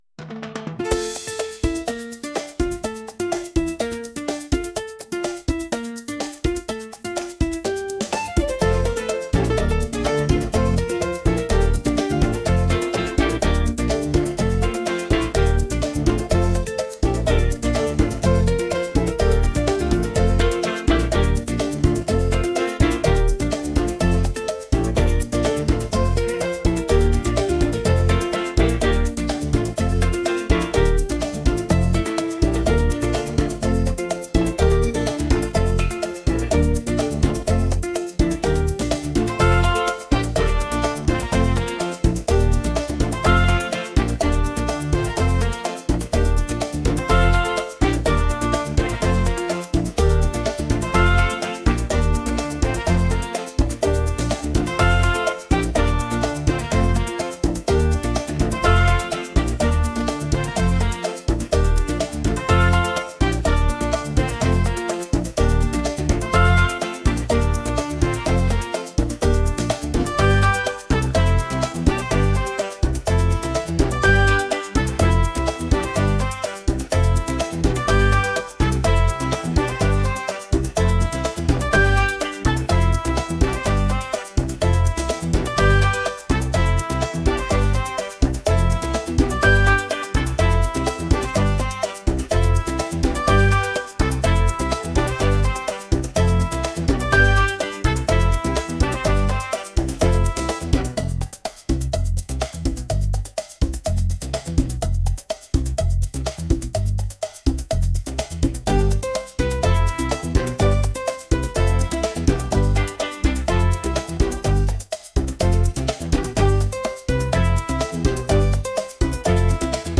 energetic | latin | pop